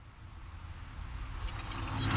slow_buildup.mp3